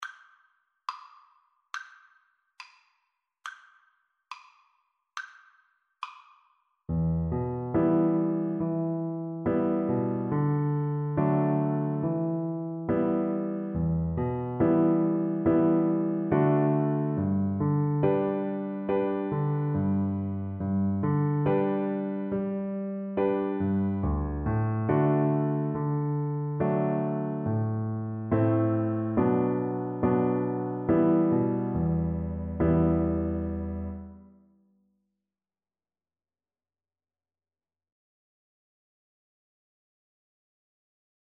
E minor (Sounding Pitch) (View more E minor Music for Cello )
2/4 (View more 2/4 Music)
Moderato